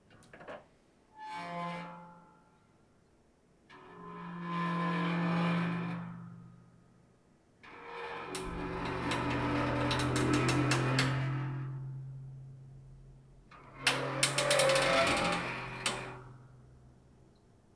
Creaking metal
metal_creak4.wav